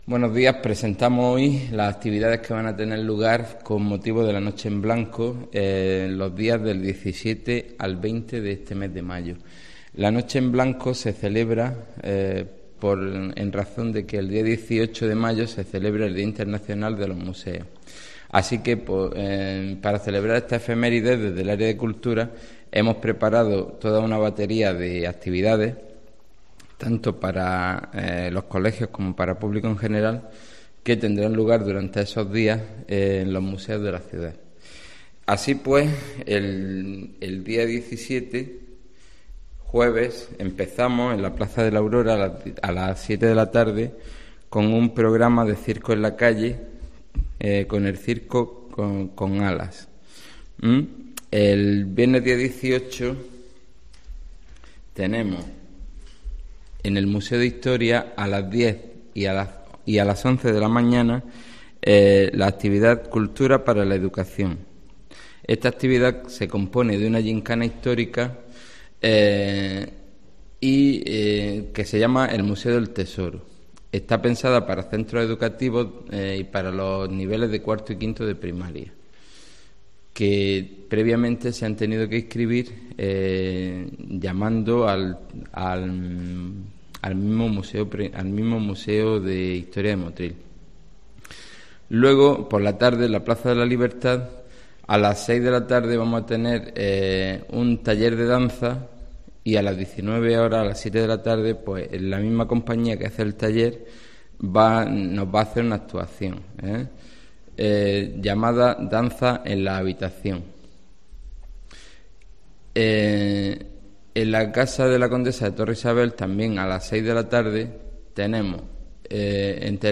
El concejal de Cultura del Ayto. de Motril, Paco Ruíz, presenta las actividades de "La Noche en Blanco", a celebrar del 17 al 20 de Mayo.